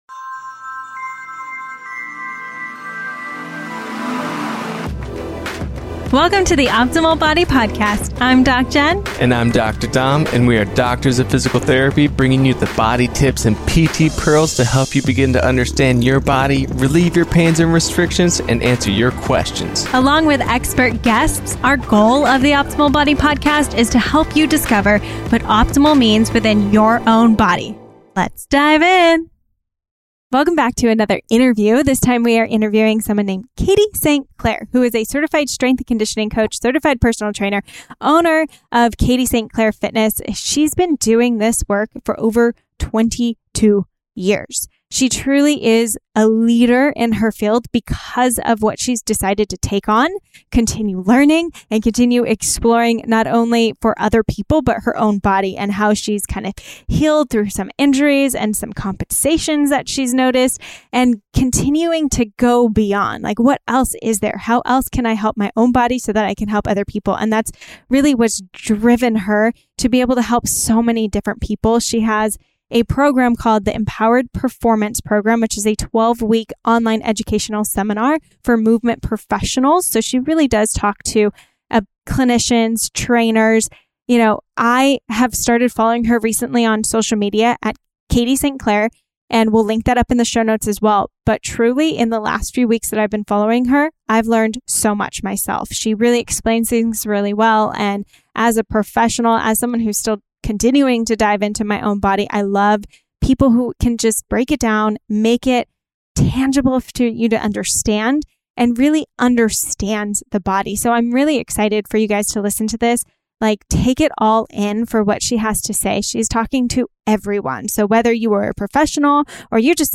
Listen to this inspiring interview